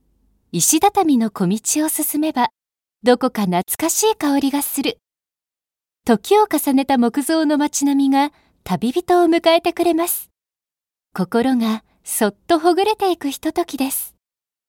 声の達人女性ナレーター
ボイスサンプル3（温かい・旅番組）[↓DOWNLOAD]